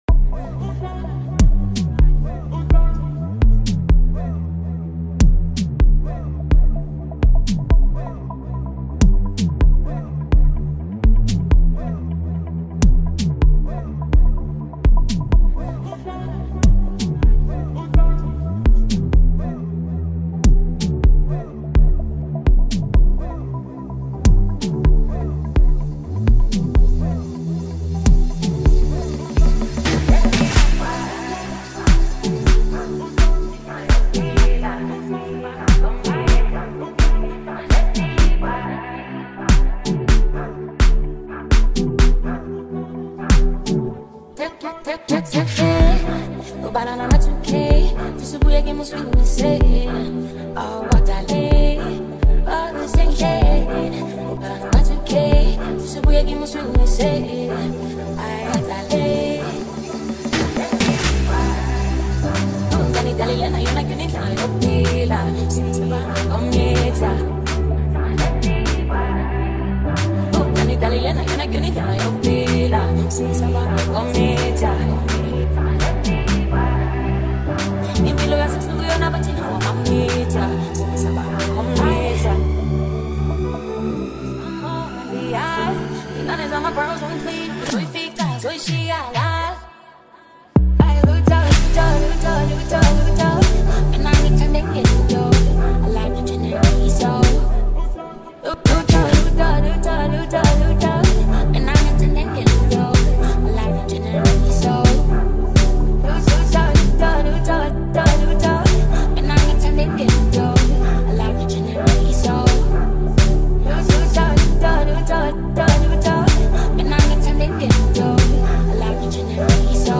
rich sound, smooth vibes, and lasting replay appeal